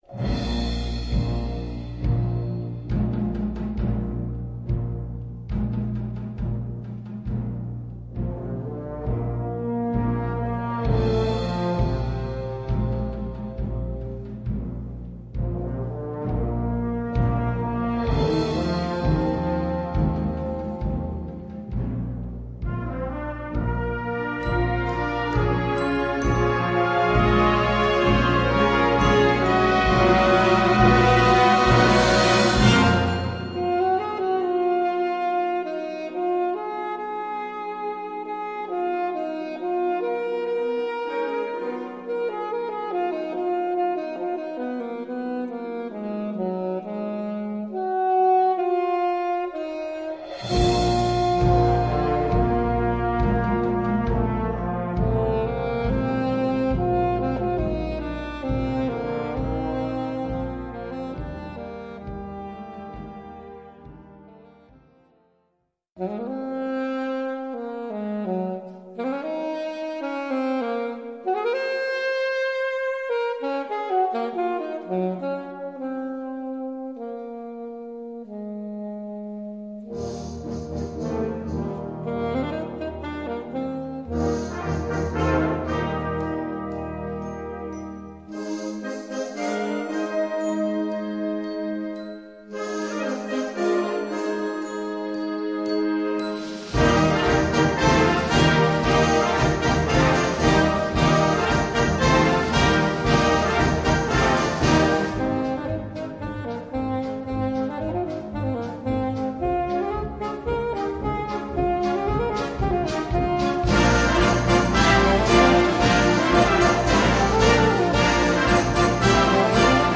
Solo für Alt- oder Tenorsaxophon
Besetzung: Blasorchester